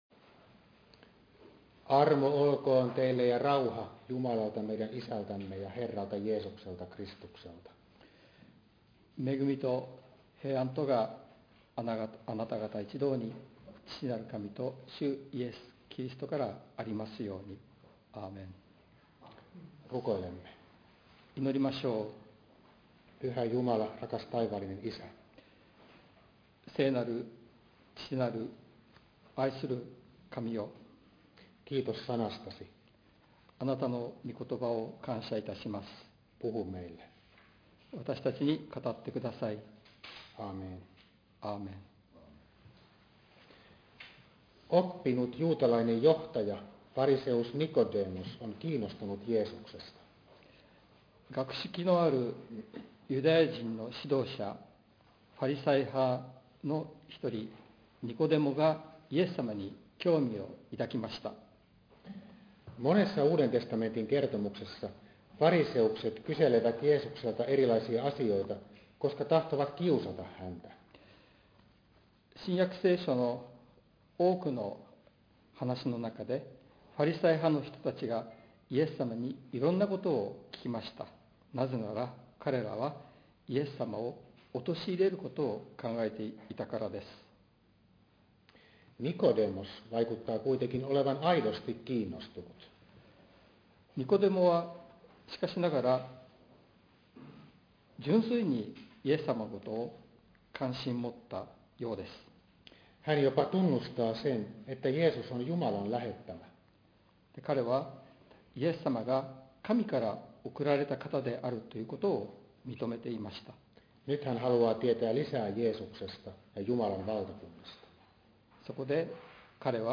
説教音声 – 福音ルーテル スオミ・キリスト教会